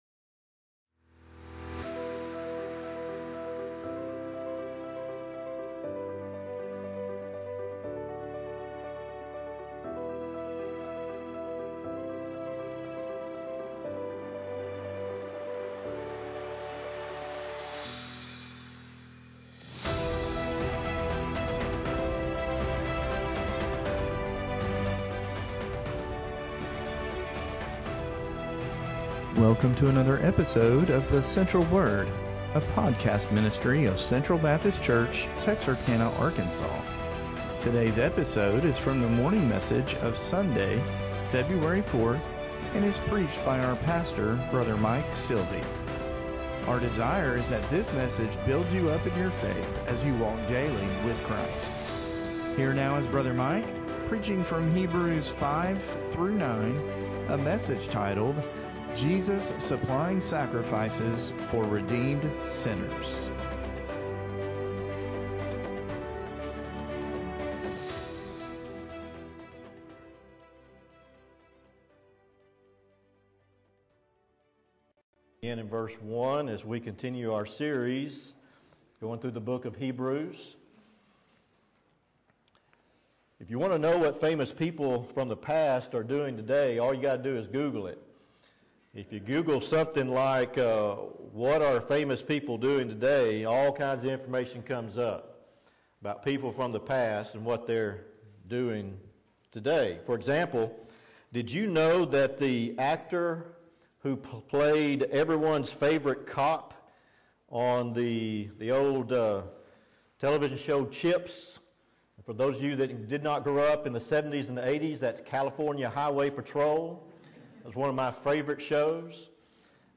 Feb4Sermon-CD.mp3